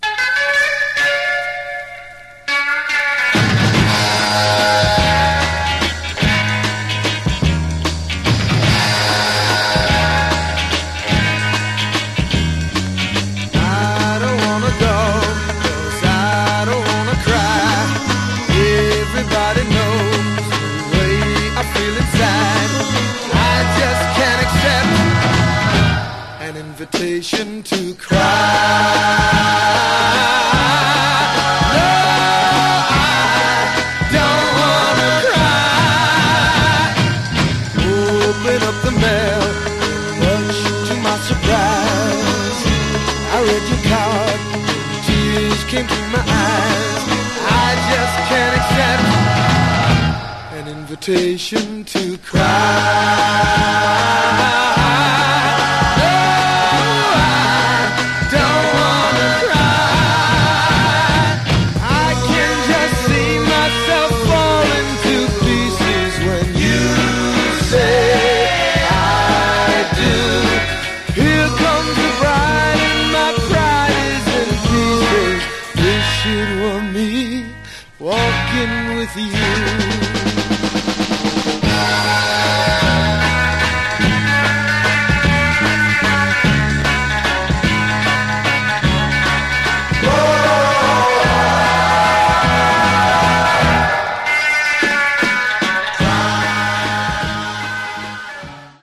Genre: Garage/Psych